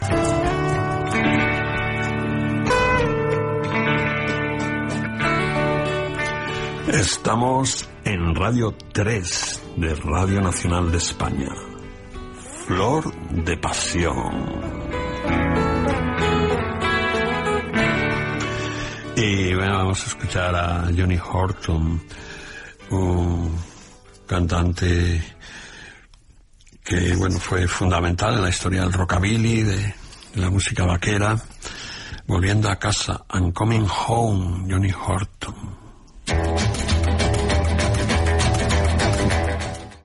Identificació del programa i tema musical
Musical